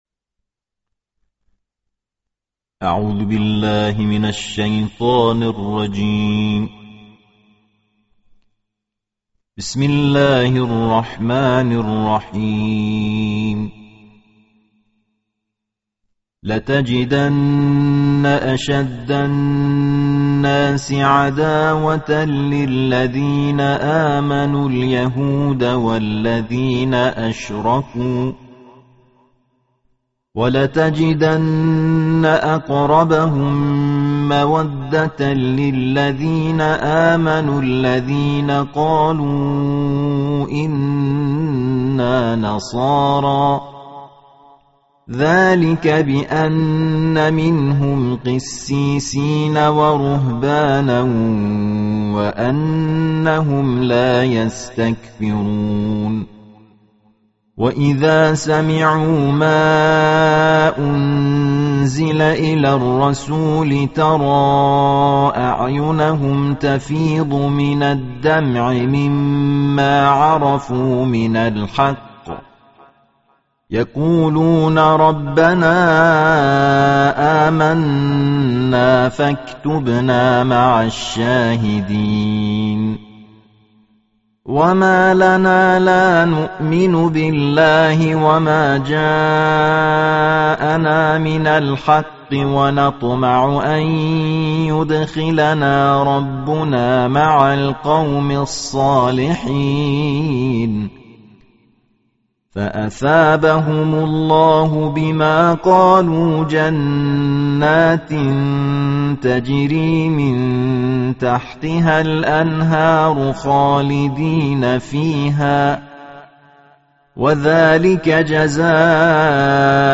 TEHERAN (IQNA) - Selama Ramadan 1401 HS, Kantor Berita Alquran menerbitkan satu juz Alquran setiap hari dengan suara salah satu qori internasional negara Iran.